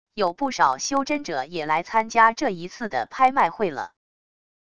有不少修真者也来参加这一次的拍卖会了wav音频生成系统WAV Audio Player